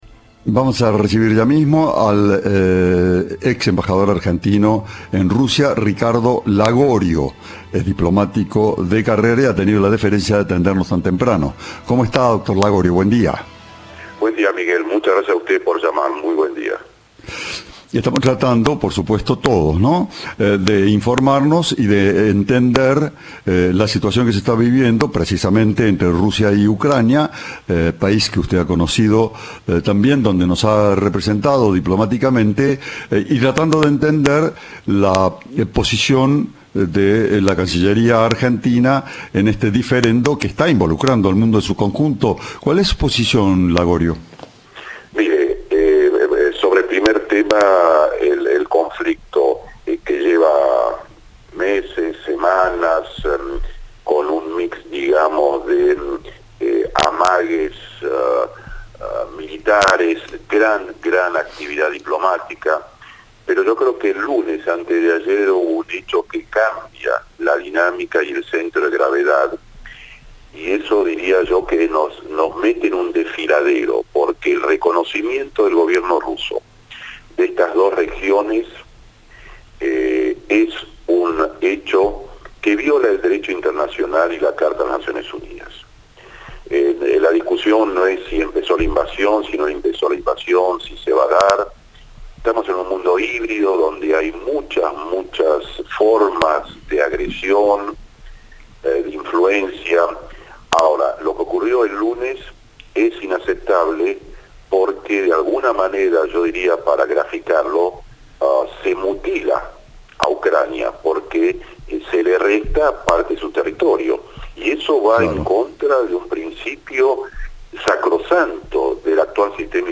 El ex embajador argentino en Rusia –durante la presidencia de Macri–, opinó sobre el conflicto entre Kiev y Moscú.